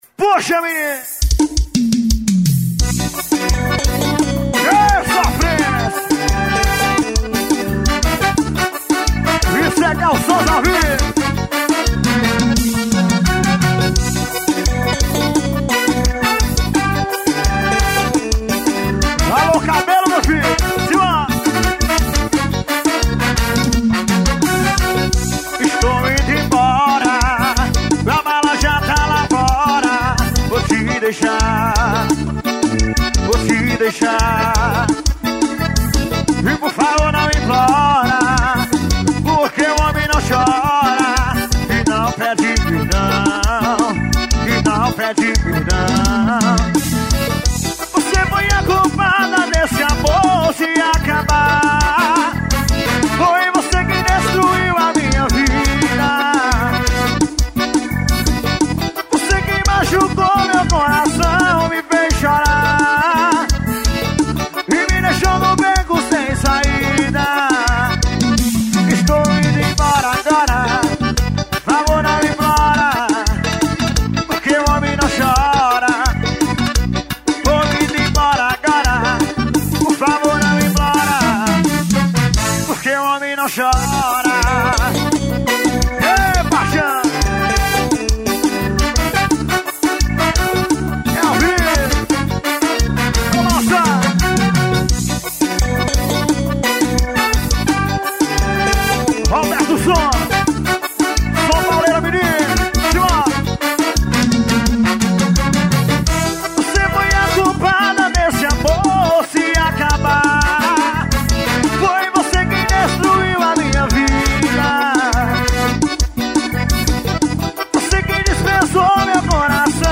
forró.